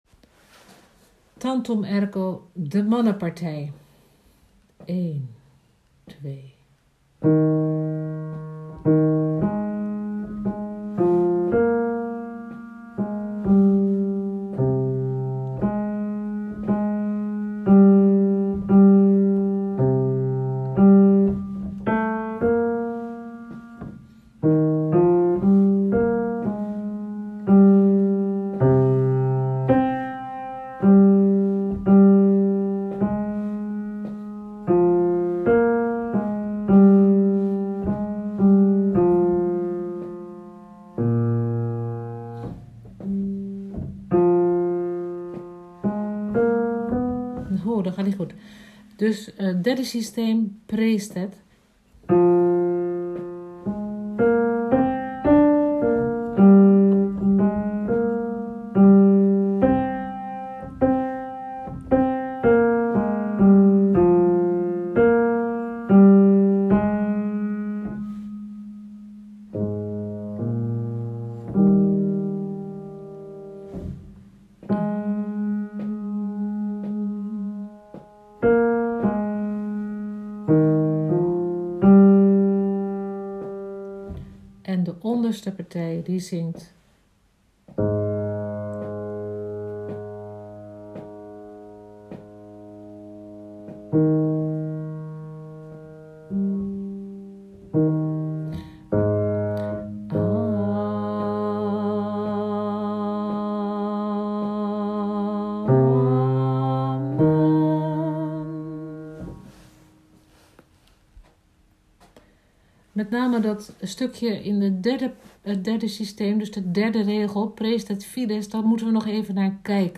tantum ergo geluid alt